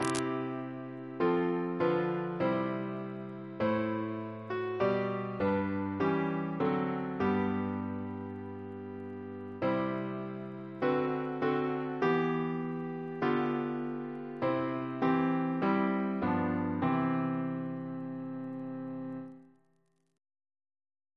CCP: Chant sampler
Double chant in C Composer: John Larkin Hopkins (1820-1873) Reference psalters: ACB: 10; PP/SNCB: 14